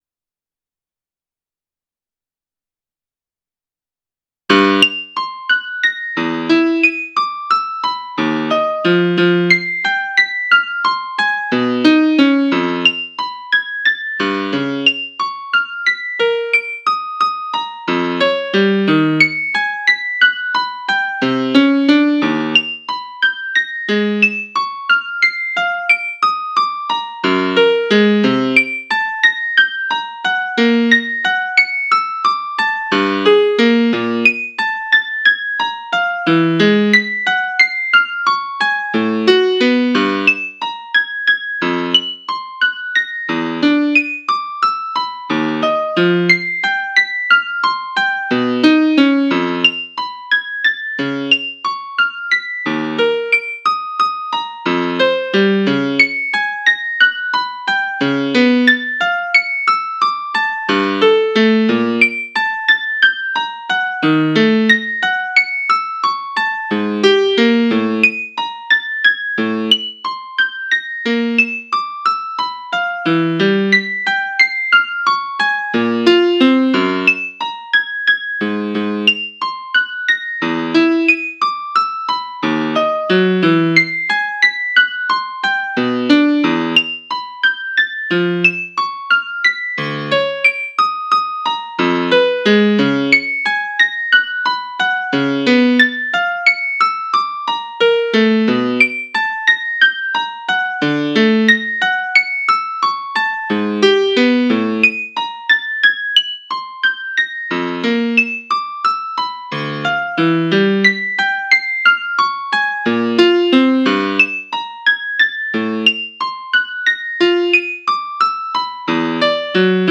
Music 1 : (Faster pace): Lower pitch represents the lower distance between 2 bounces
1noteper033sec.wav